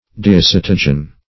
Search Result for " diisatogen" : The Collaborative International Dictionary of English v.0.48: Diisatogen \Di`i*sat"o*gen\, n. [Pref. di- + isatine + -gen.]